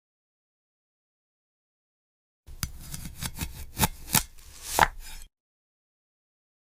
Amberheart slicing ASMR from Grow